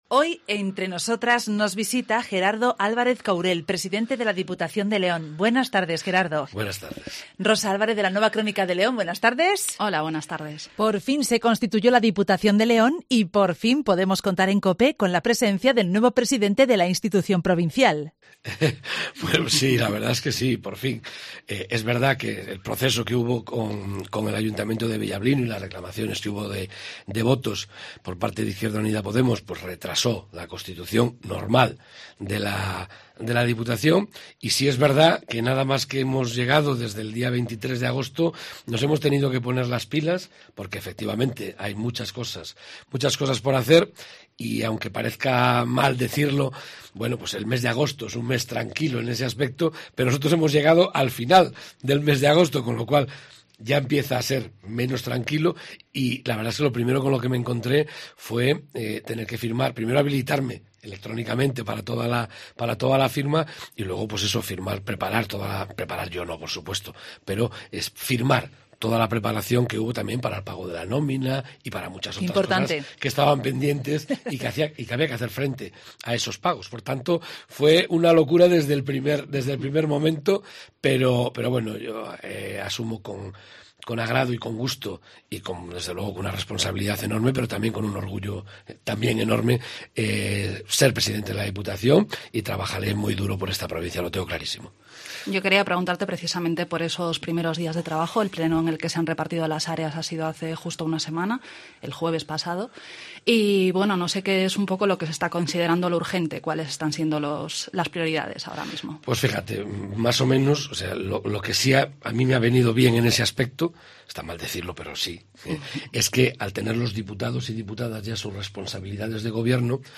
El presidente de la Diputación de León, Gerardo Álvarez Courel, visita Herrera y Mediodía en Cope León para participar "Entre Nosotras".